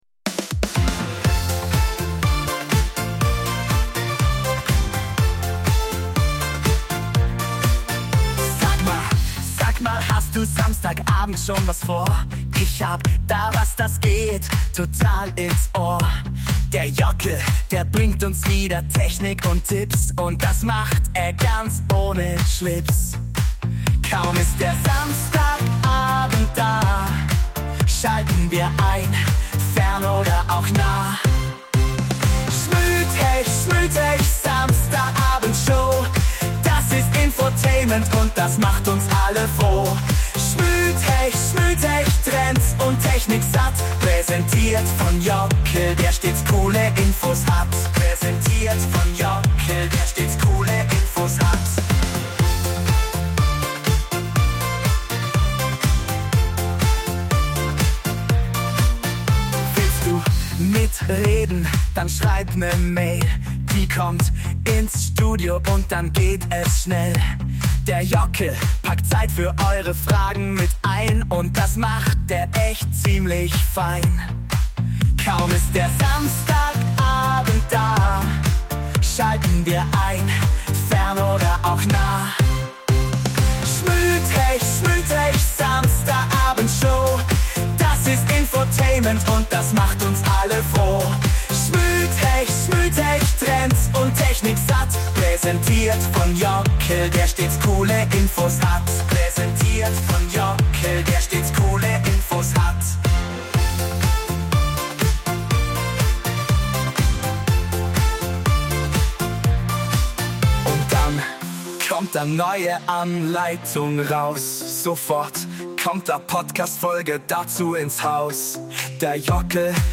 This week we are bringing you something a little different, our first author interview!